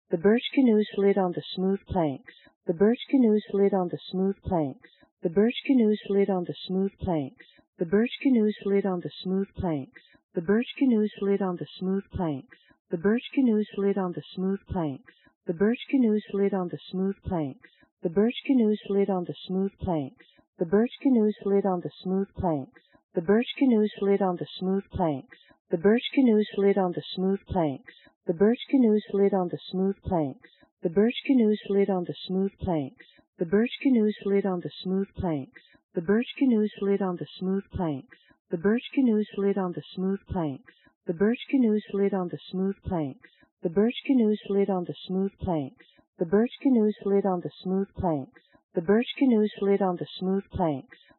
The left and right HRTF filters are applied separately to a mono recording. A stereo file is created by concatenating the results using the SOX utility.
Expected results after compression and decompression by the VLC 3D 48 codec
(stereo output mode) at 24000 bps
reference_female_2_vlc_v7_3d_48_st.mp3